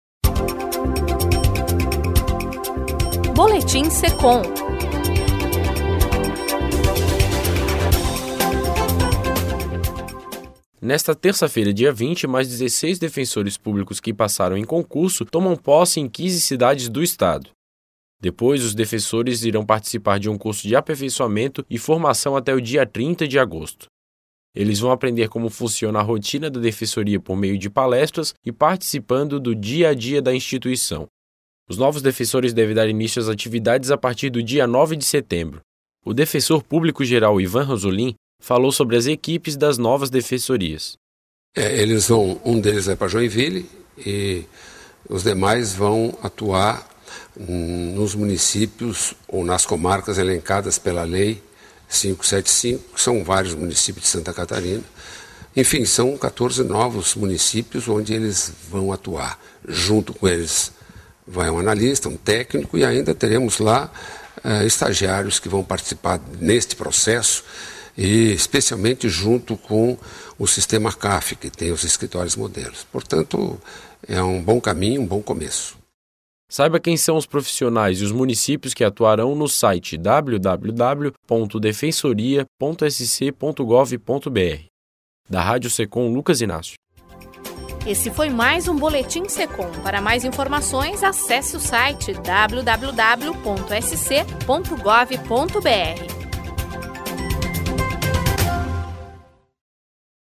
Ouça e baixe a entrevista do Defensor Geral, Ivan Ranzolin: